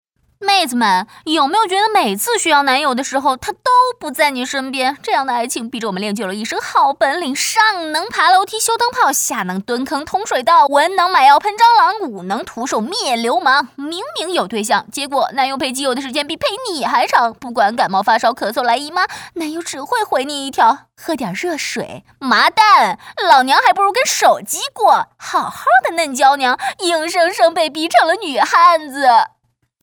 宣传片配音
病毒配音